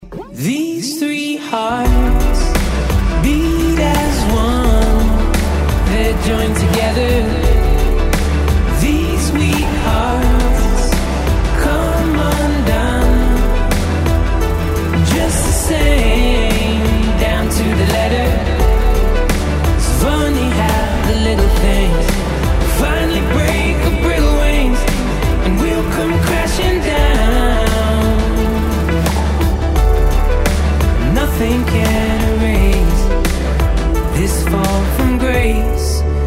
Категория: Спокойные рингтоны